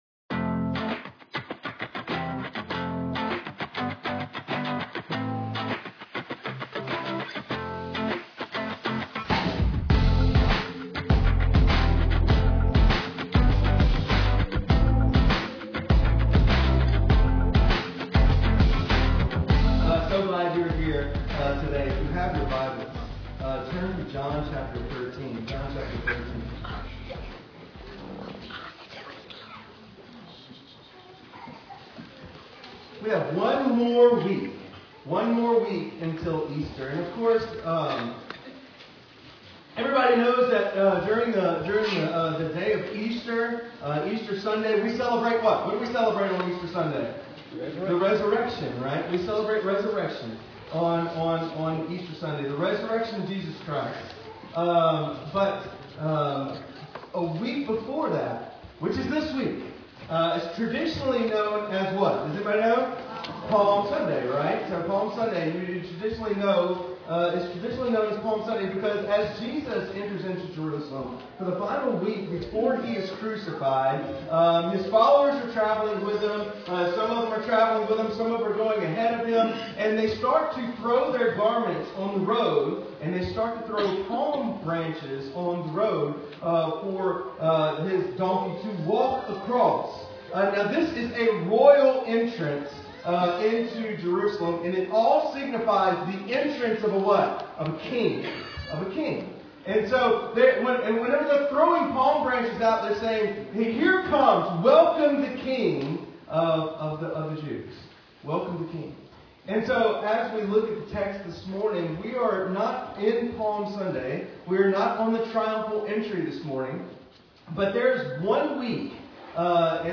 Sermons | Trace Creek Baptist Church